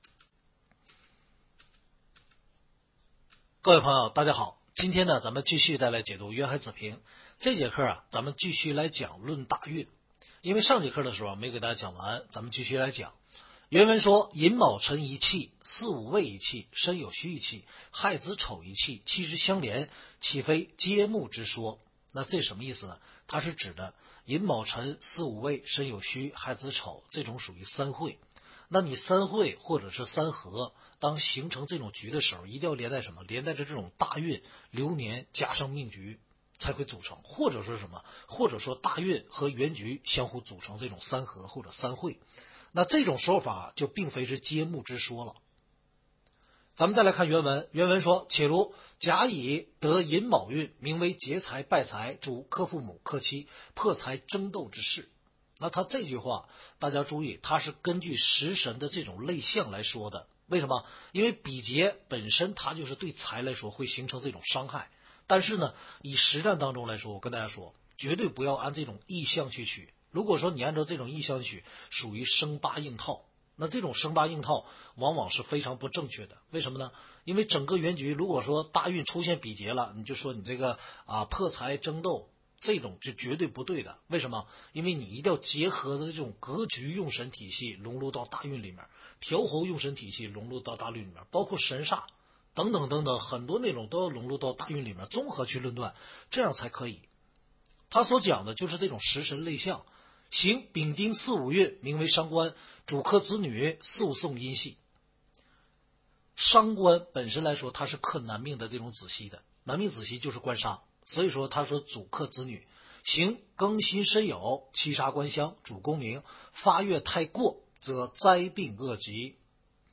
听书渊海子平白话文